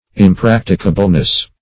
Impracticableness \Im*prac"ti*ca*ble*ness\, n.